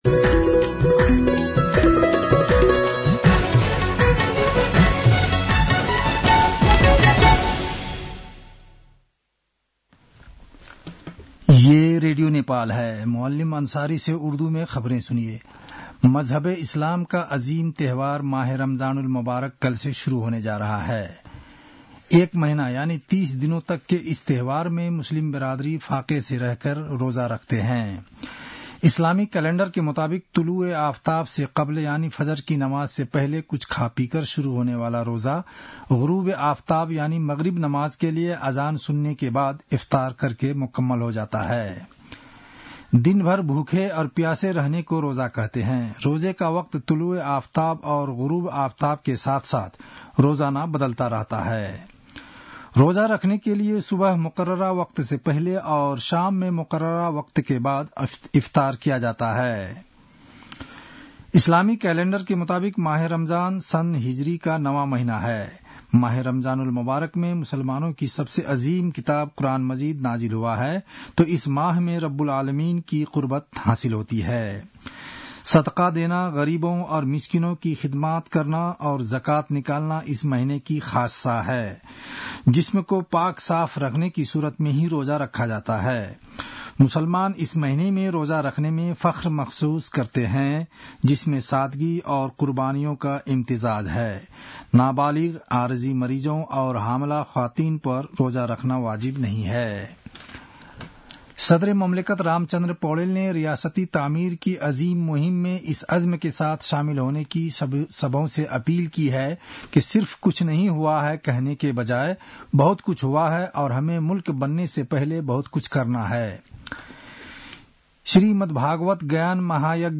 उर्दु भाषामा समाचार : १८ फागुन , २०८१